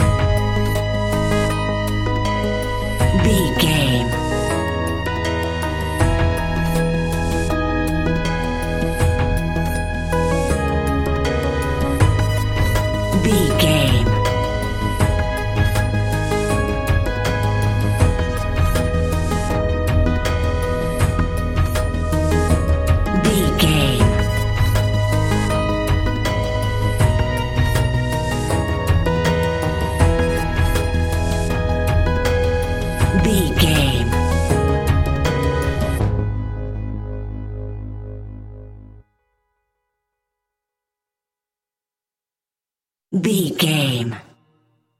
Aeolian/Minor
G#
ominous
dark
eerie
electric piano
percussion
drums
synthesiser
strings
horror music